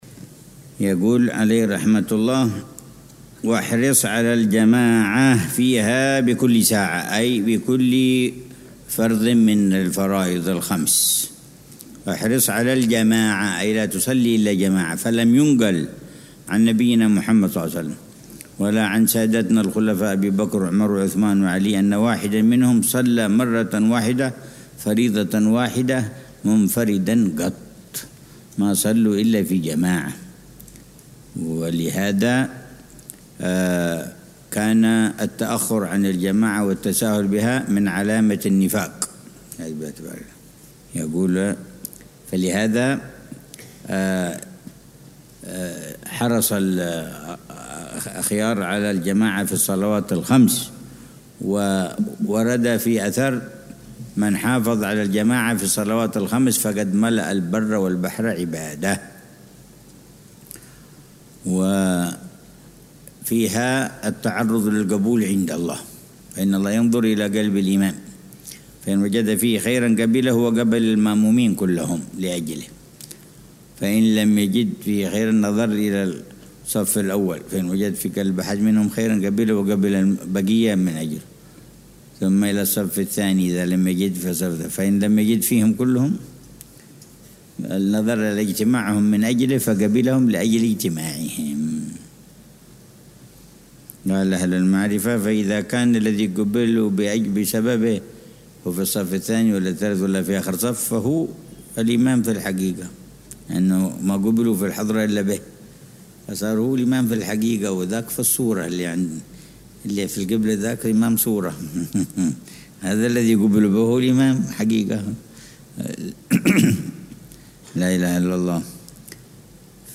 شرح الحبيب عمر بن حفيظ على منظومة «هدية الصديق للأخ والرفيق» للحبيب عبد الله بن حسين بن طاهر. الدرس التاسع عشر ( 7 صفر 1447هـ)